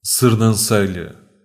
Sernancelhe (Portuguese pronunciation: [sɨɾnɐ̃ˈsɐʎɨ]
Pt-pt_Sernancelhe_FF.ogg.mp3